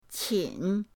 qin3.mp3